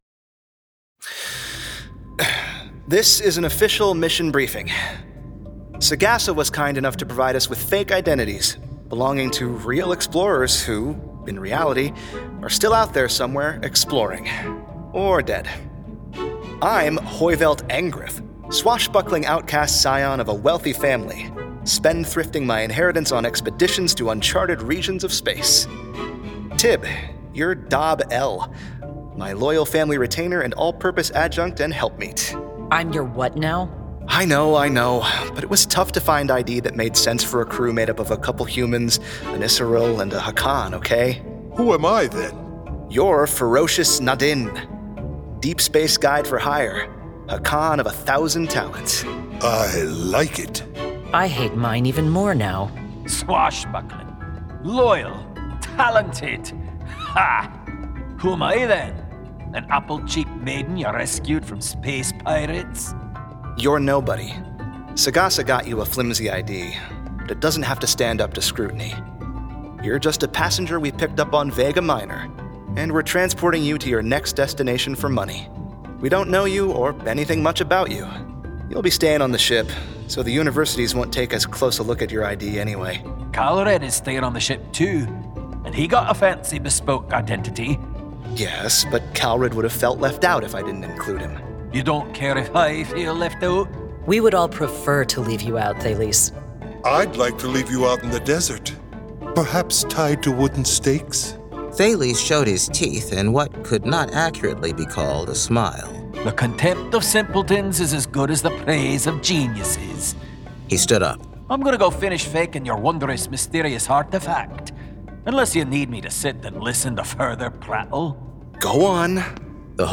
Full Cast. Cinematic Music. Sound Effects.
Genre: Science Fiction